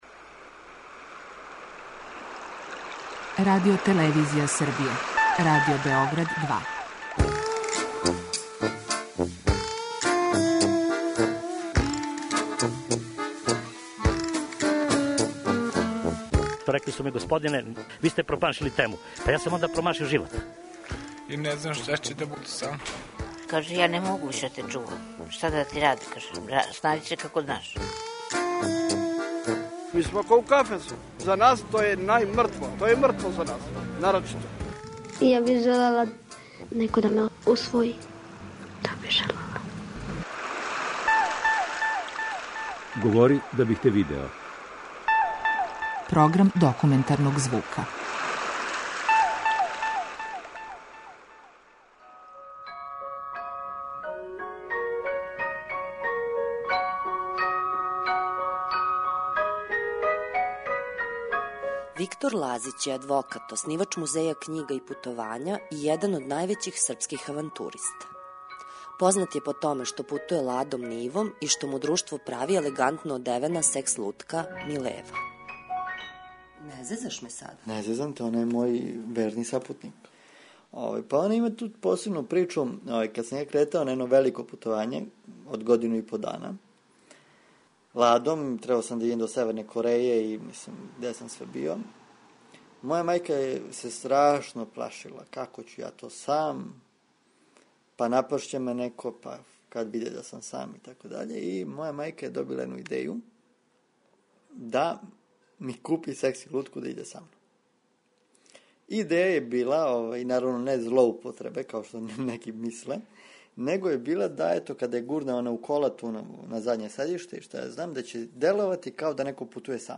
Документарни програм
dokumentarni u.mp3